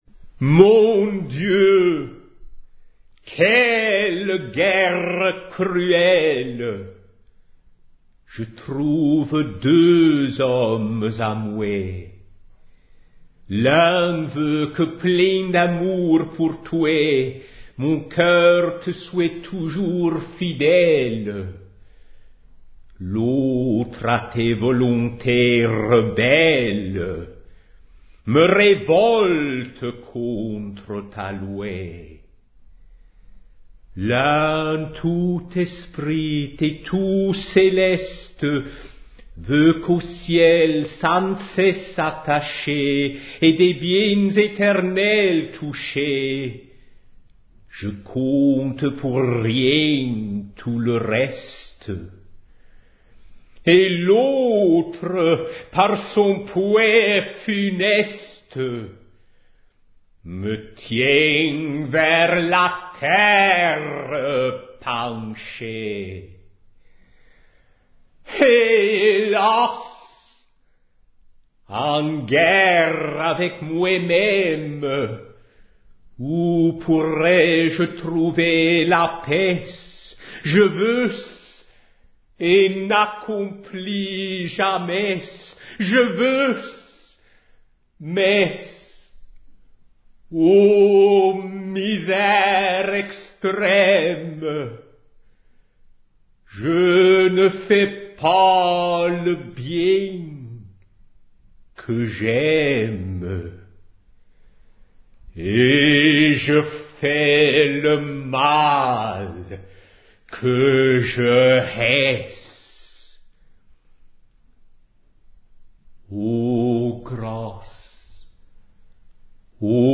La parole chantée
Ecouter Eugène Green dans sa reconstitution
de la déclamation baroque